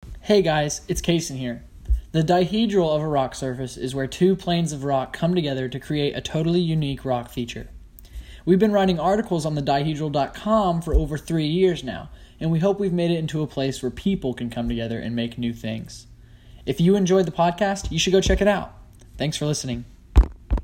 Fabulous sounding voices, all of them.